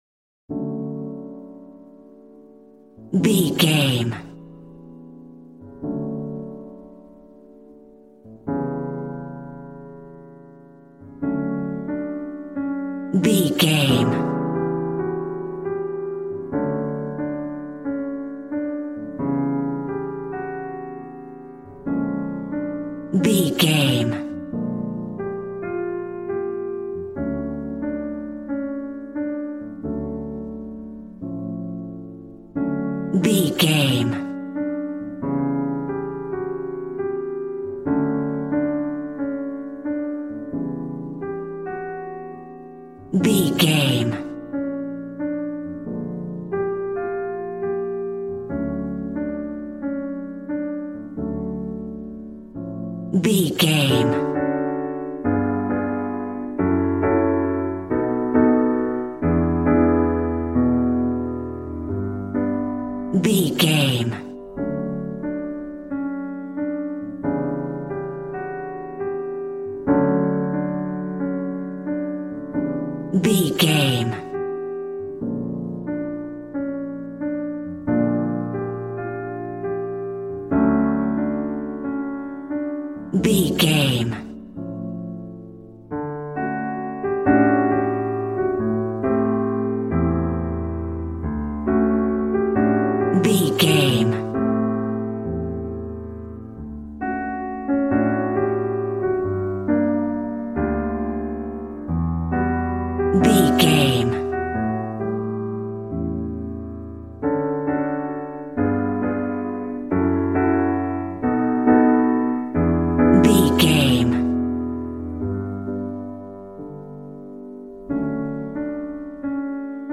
Smooth jazz piano mixed with jazz bass and cool jazz drums.,
Ionian/Major
D